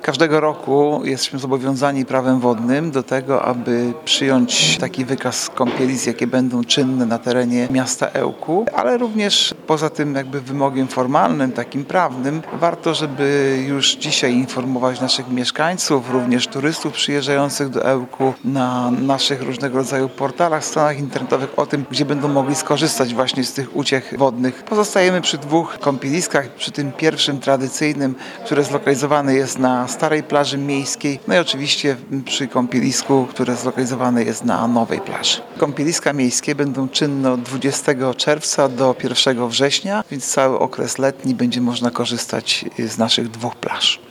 – Oba kąpieliska czynne będą od 20 czerwca do 1 września – mówi Artur Urbański, zastępca prezydenta Ełku.